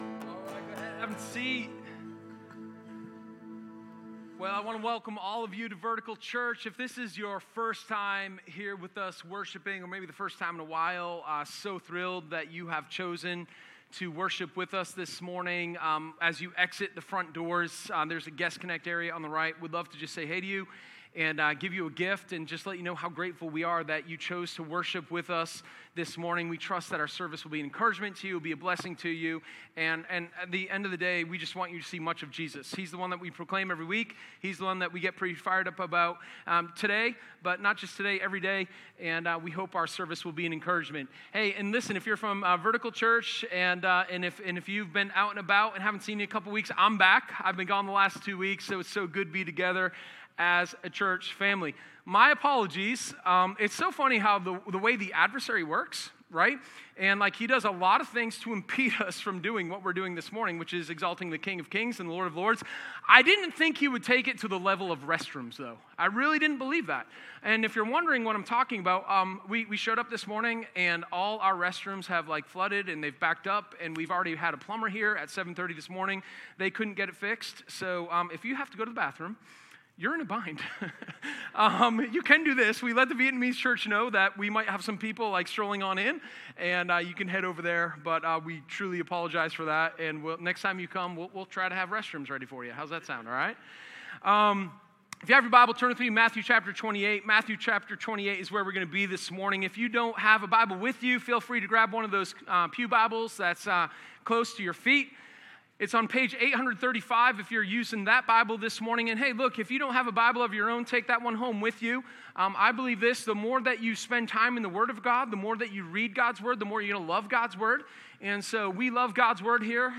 Sermon04_04.m4a